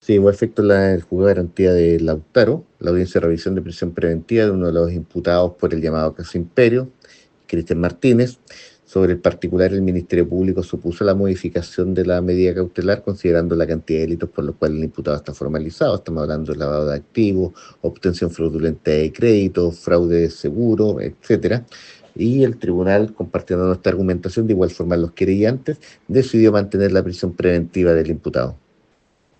La información la confirmó a Radio Bío Bío el fiscal Miguel Ángel Velásquez, explicando que se opusieron al cambio de cautelar considerando la cantidad de delitos imputados y la gravedad de los hechos investigados.